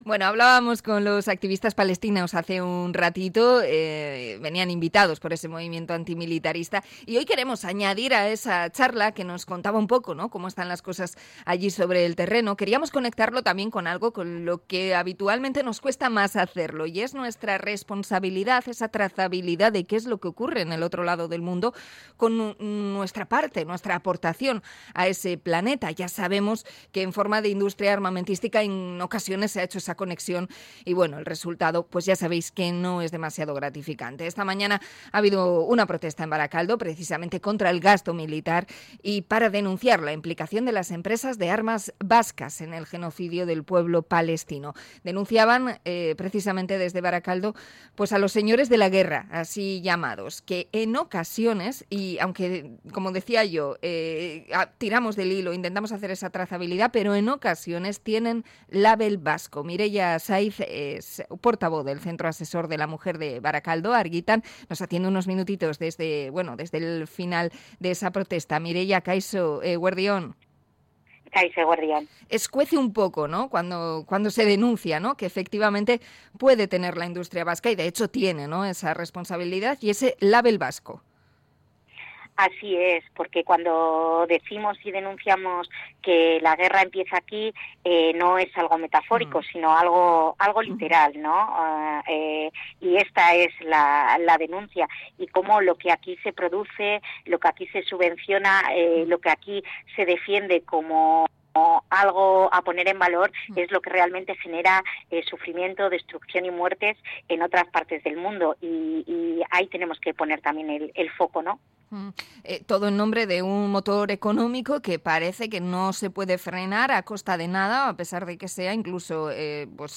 Entrevista al movimiento antimilitarista por las armas con Label vasco